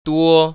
[duō]
뚜오